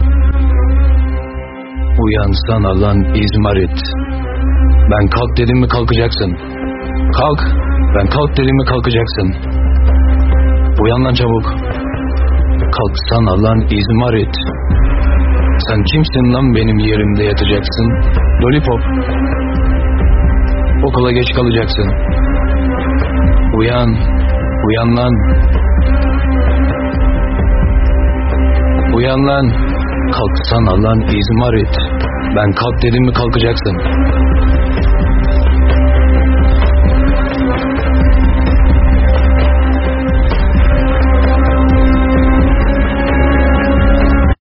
Kategori: Zil Sesleri